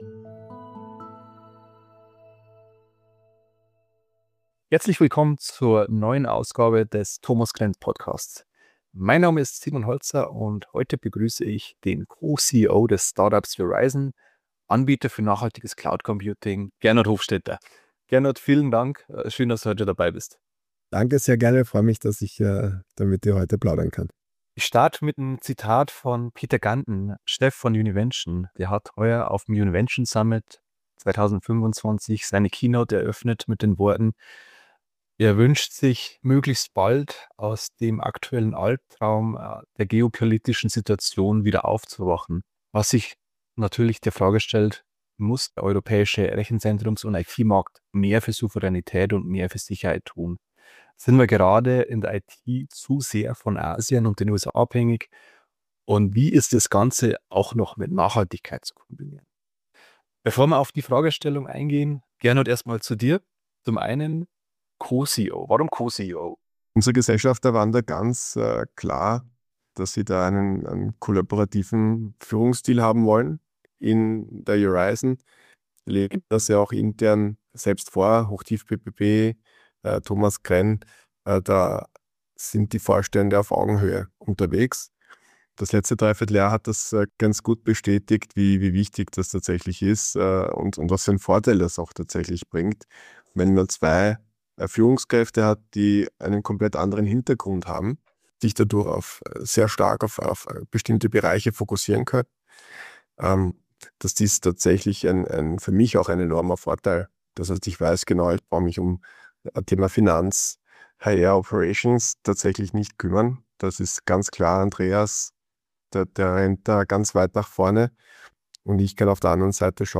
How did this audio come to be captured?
He reminds the listener how to stay awake to life and understand more deeply the traps of a deluded mind. This episode was recorded during a weekly online meeting of questi…